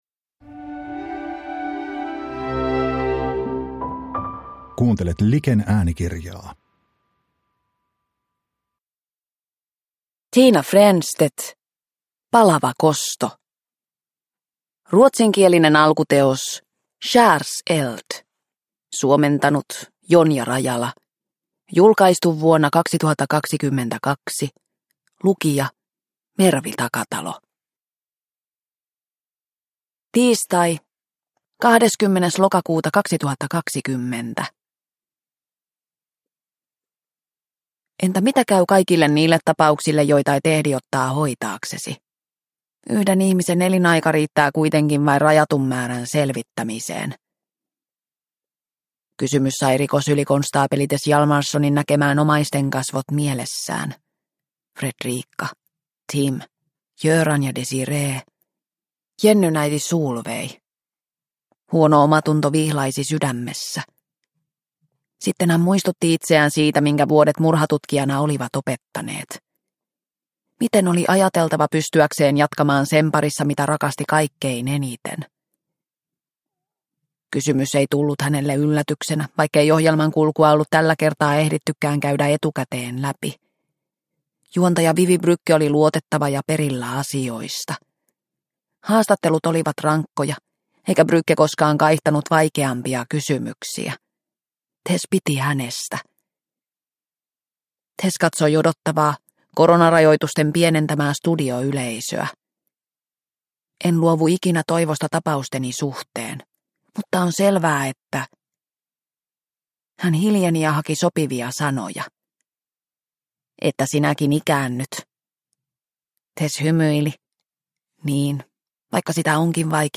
Palava kosto – Ljudbok – Laddas ner